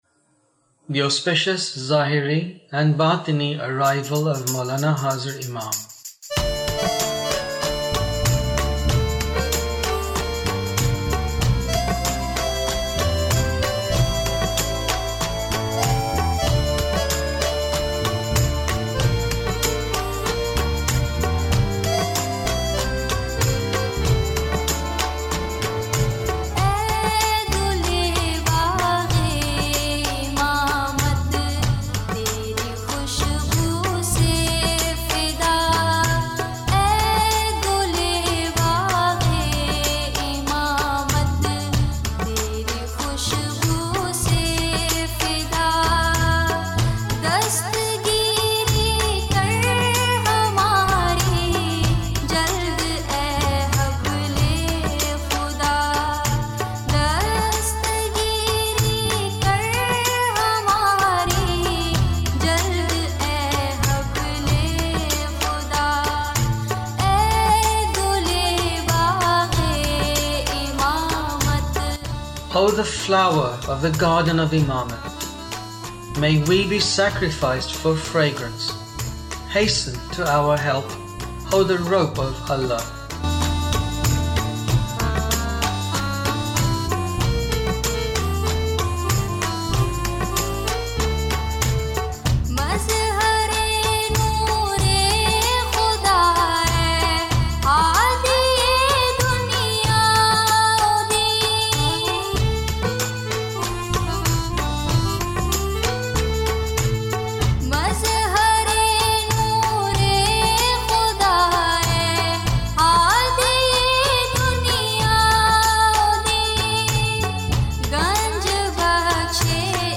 Devotional Song with English Translation: Aey Gule Bag-e Imamat
Excellent! Such a wonderful Poetry in the praise of Mawla and what a beautiful and soul-refreshing voice and excellent composition as well!